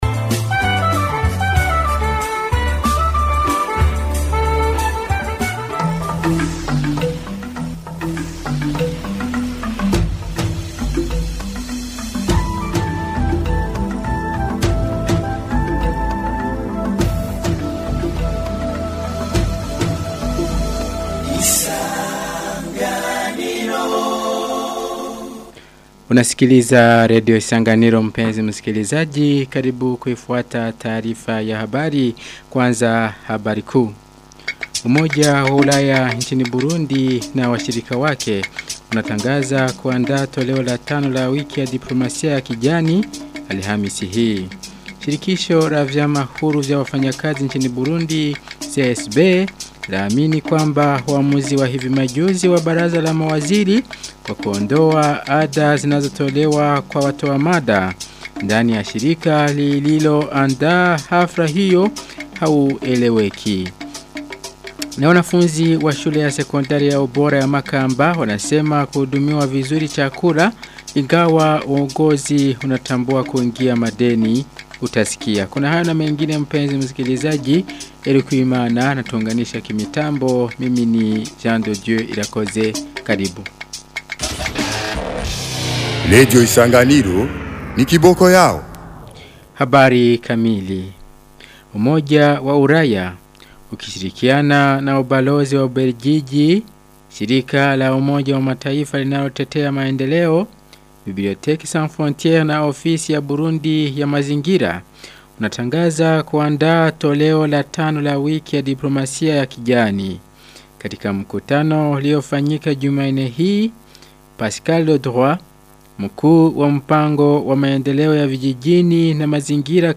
Taarifa ya habari ya tarehe 28 Oktoba 2025